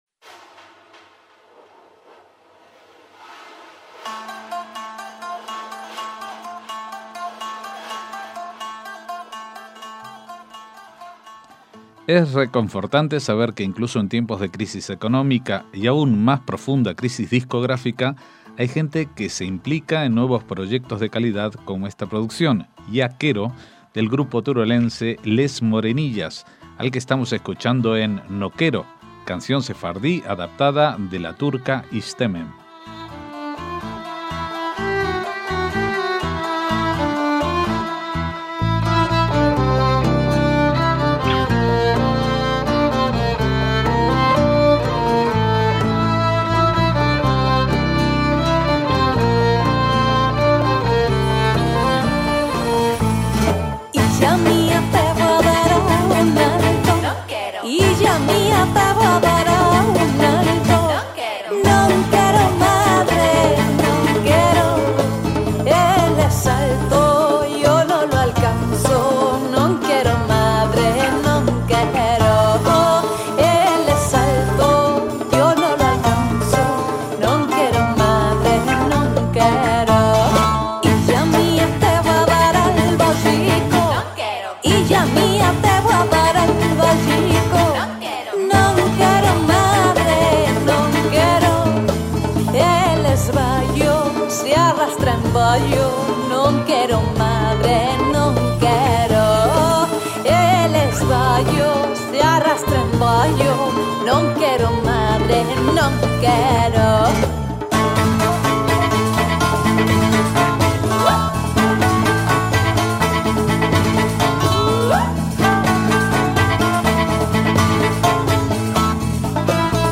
MÚSICA SEFARDÍ
violín y voz
guitarra flamenca
voz y percusiones
bajo